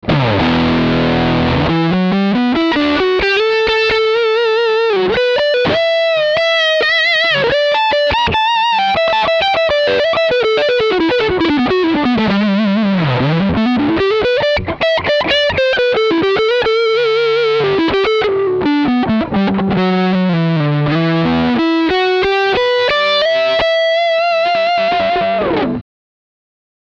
Die Yamaha Revstar RS820CR liefert sehr gute Rock- und Blues Sounds.
Für Fans von warmen, cremigen Gitarrensounds wird die Yamaha Revstar RS820CR ein zufriedenstellendes Ergebnis liefern.
Zwar lassen sich durch den Dry Switch Bässe herausfiltern, jedoch klingen die Tonabnehmer etwas dumpf für meine Ohren.
Yamaha Revstar RS820CR Soundbeispiele